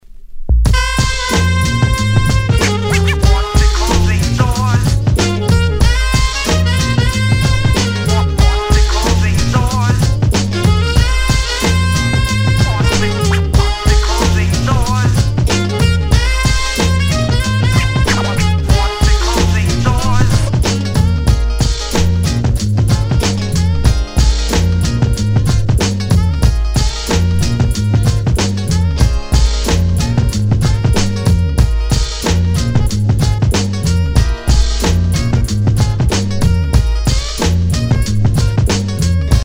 Tag       HIP HOP NEWSCHOOL